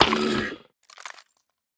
sounds / mob / turtle / death2.ogg